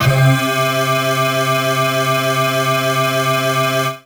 55bg-syn11-c3.wav